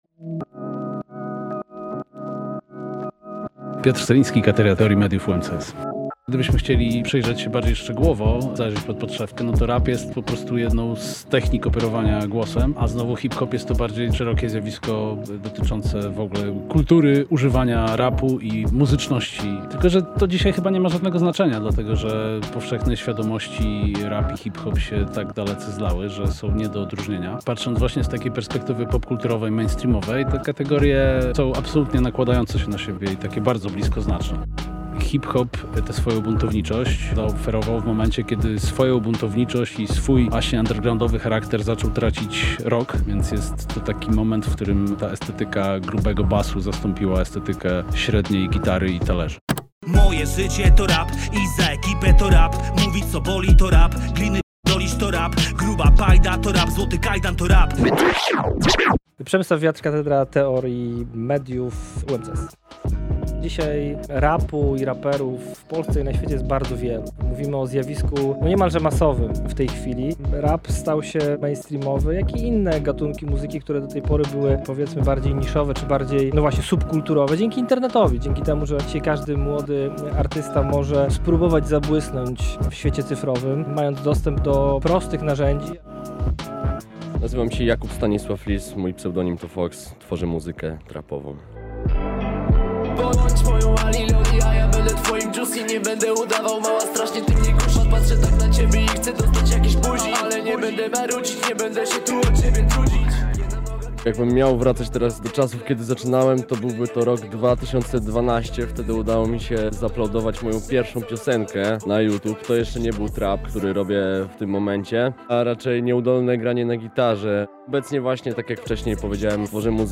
Na te i więcej pytań odpowiedzą sympatycy i artysta.